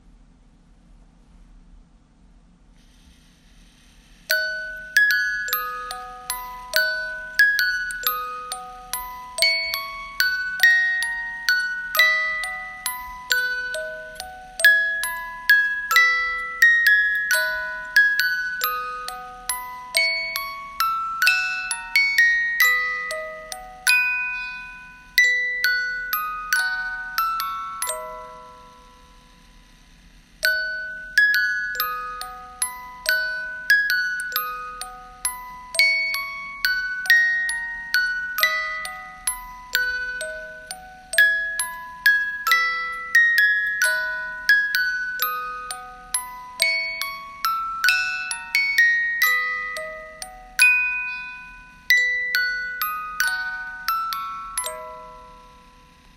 标签： 圣诞 平安夜 温馨 浪漫 影视 广告 短视频 校园
声道立体声